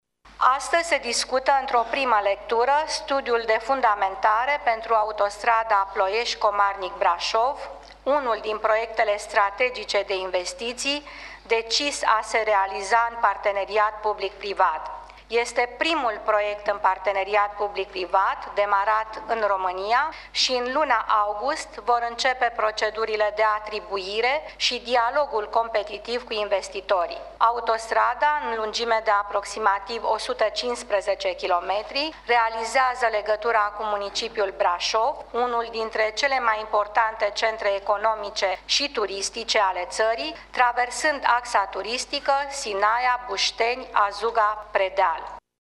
Premierul Viorica Dăncilă: